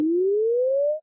arc_grow_short.ogg